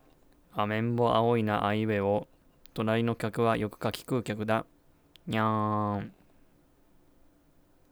indexマイク